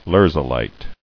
[lher·zo·lite]